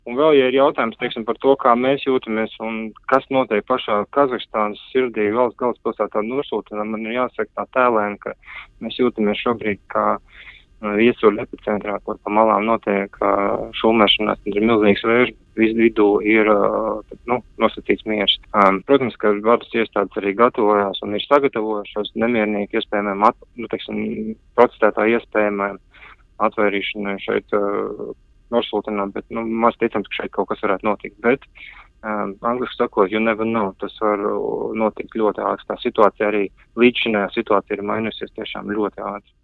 RADIO SKONTO Ziņās par Latvijas diplomātiem Kazahstānā - Radio Skonto